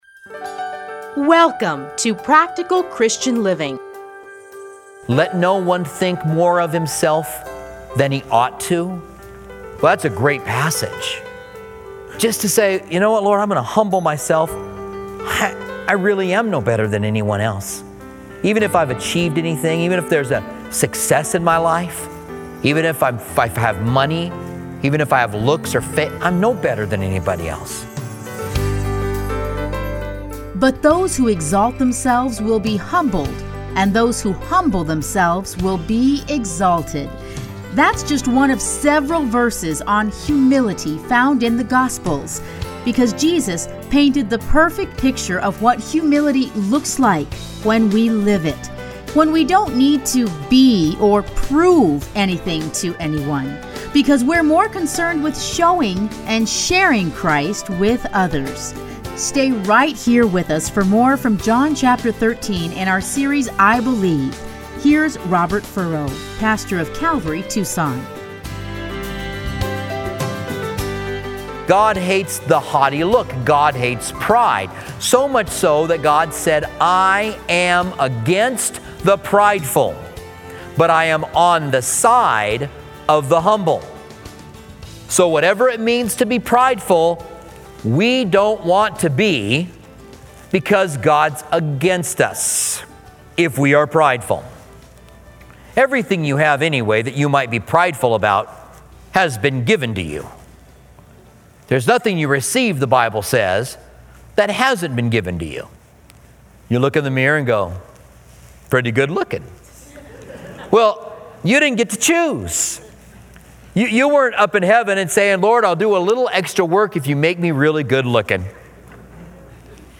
radio programs